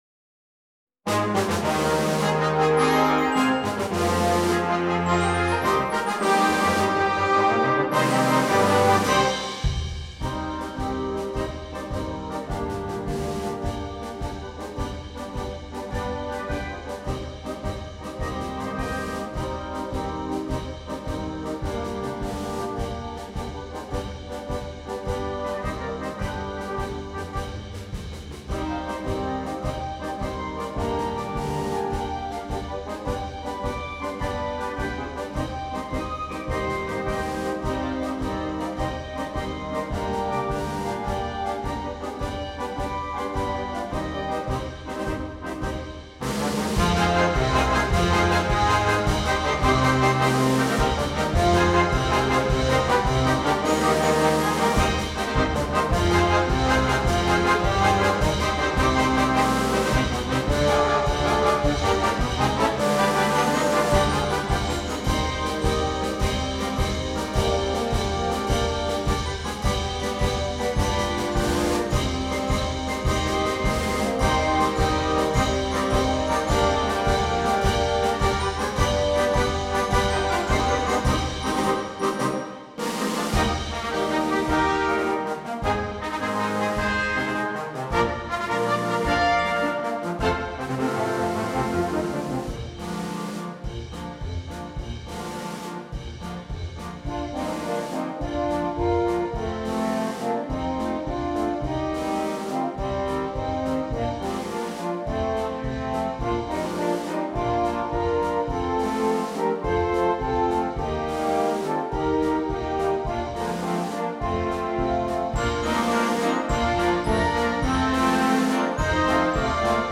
Konzertmarsch für großes Blasorchester